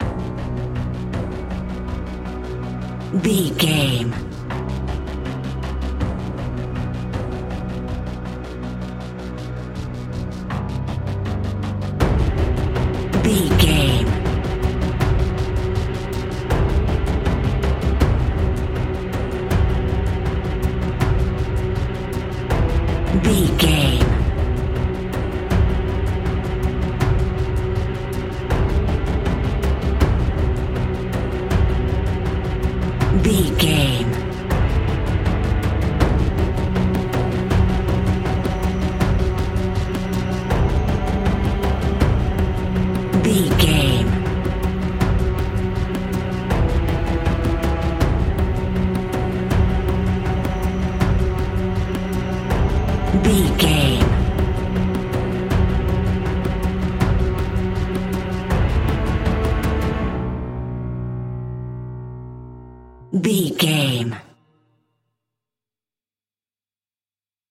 Aeolian/Minor
ominous
dark
eerie
drums
synthesiser
piano
horror music